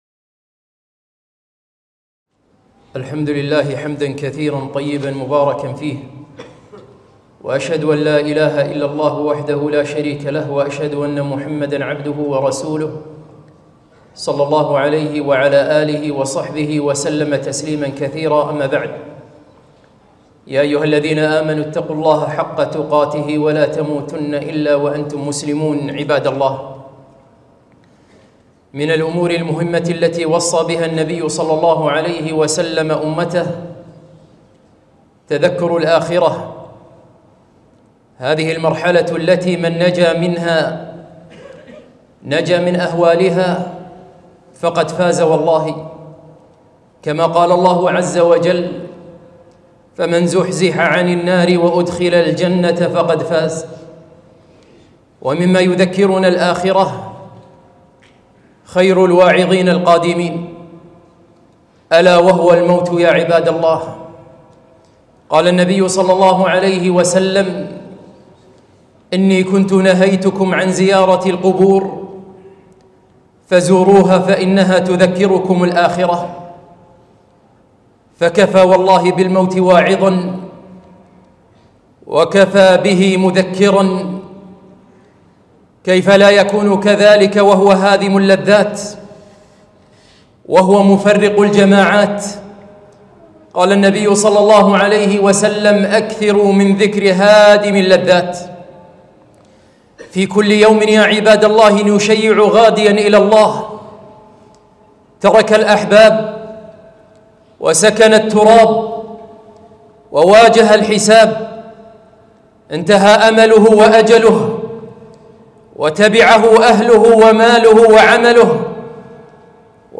خطبة - تذكروا الموت